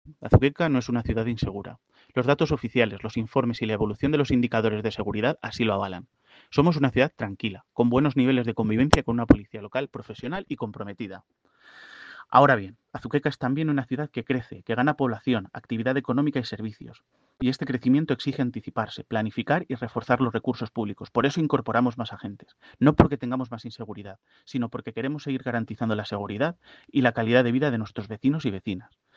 Declaraciones del alcalde sobre la seguridad en Azuqueca